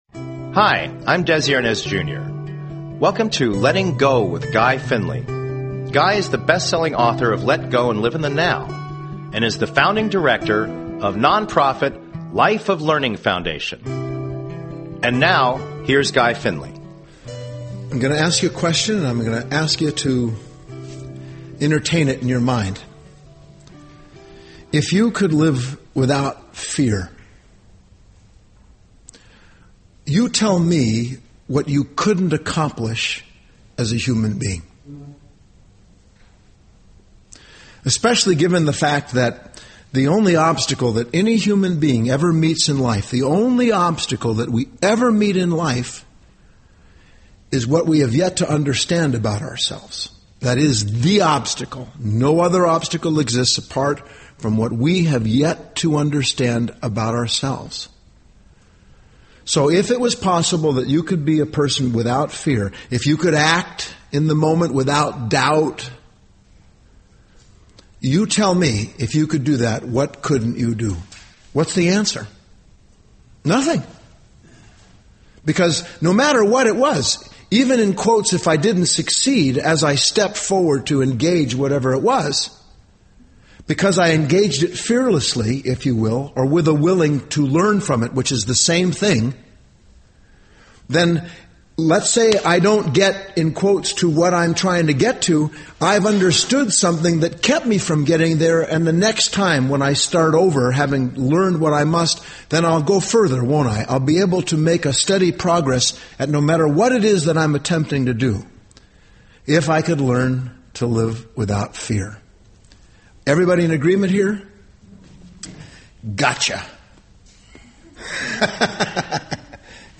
Subscribe Talk Show Letting Go with Guy Finley Show Host Guy Finley GUY FINLEY’s encouraging and accessible message is one of the true bright lights in our world today.